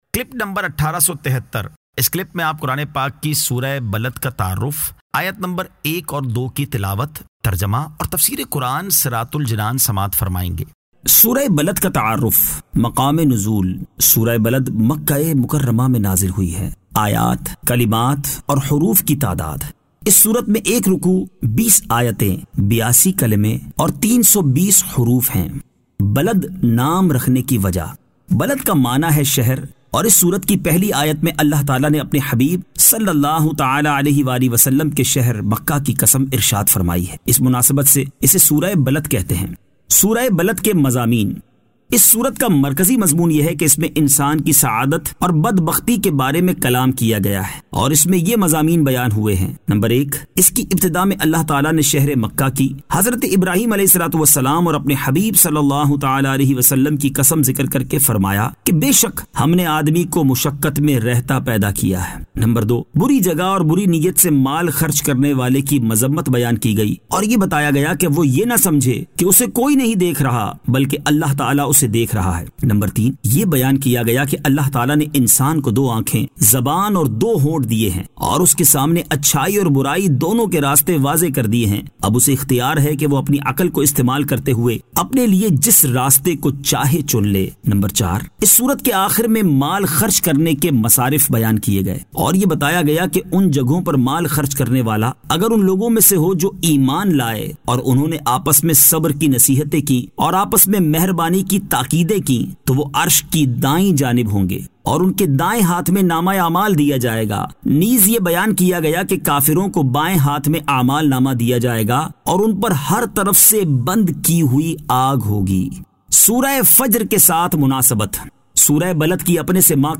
Surah Al-Balad 01 To 02 Tilawat , Tarjama , Tafseer